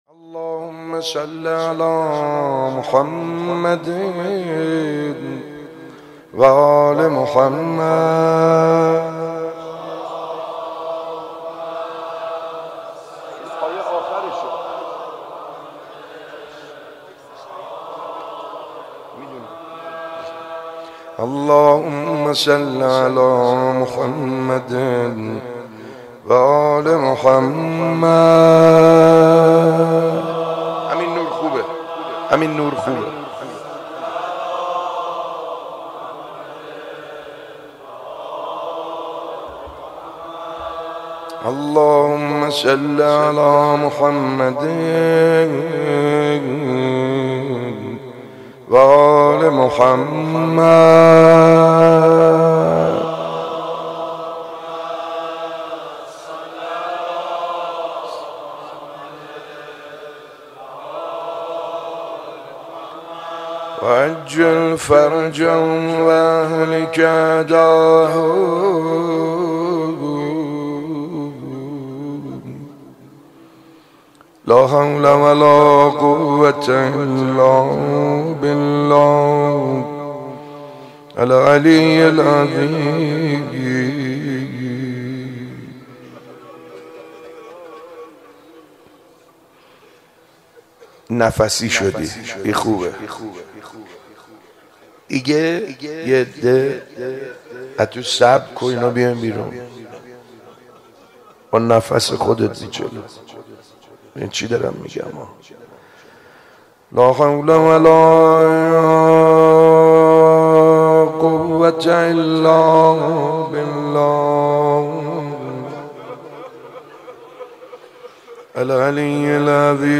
شب اول ماه رمضان 95_مناجات خوانی